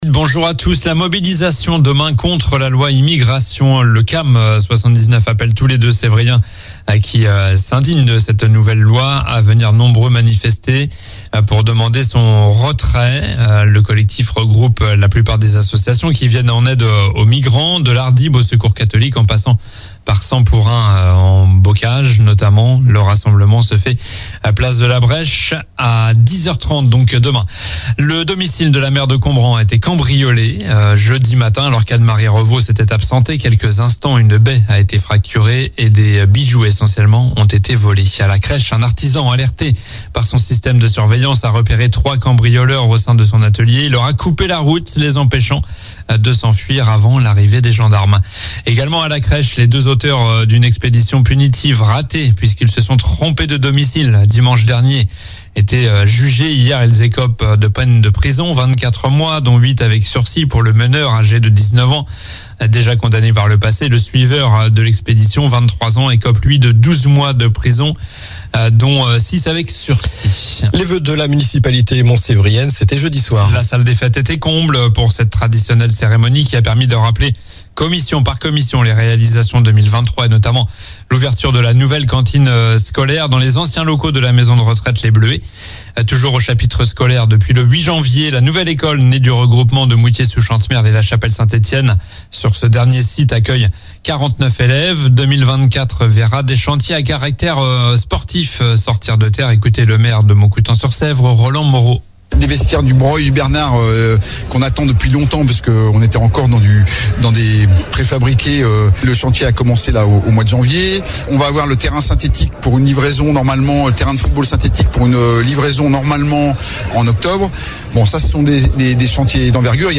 COLLINES LA RADIO : Réécoutez les flash infos et les différentes chroniques de votre radio⬦
Journal du samedi 20 janvier (matin)